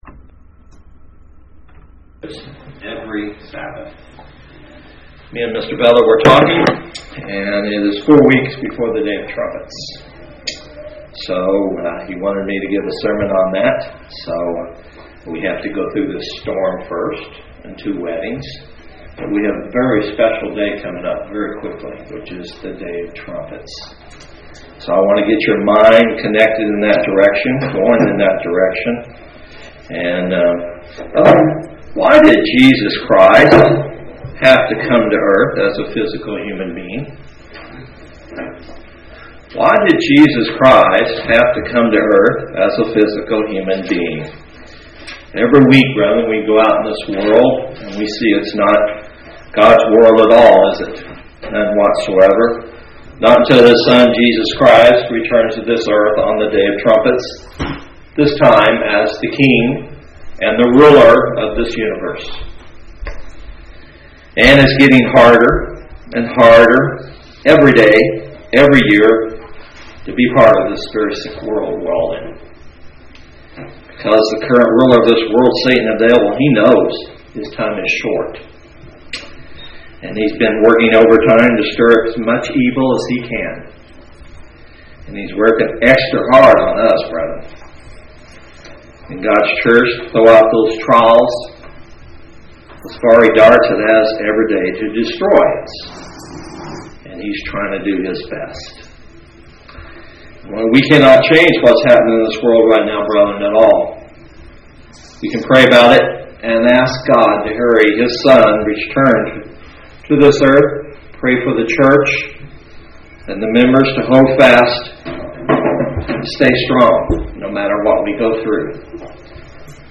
Feast of Trumpets is coming in a few weeks and this sermon helps us get our mind on the Big Picture.